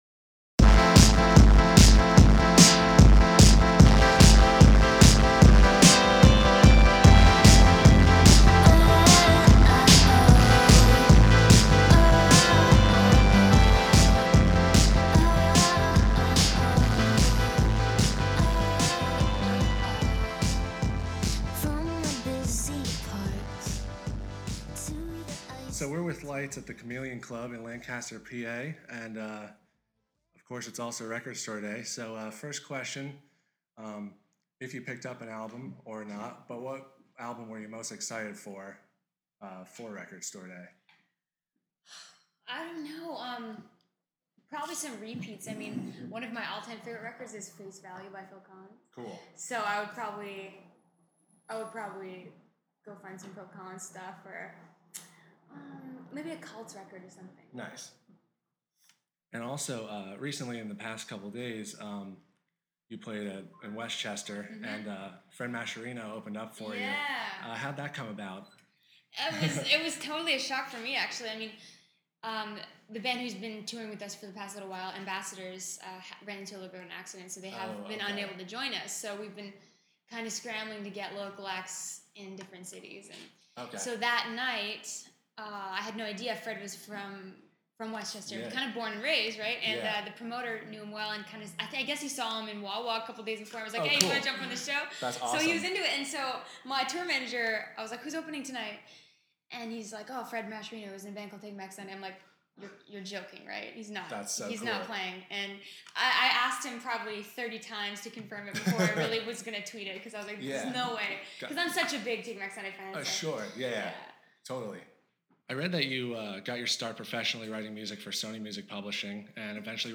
Exclusive: Lights Interview
16-interview-lights.mp3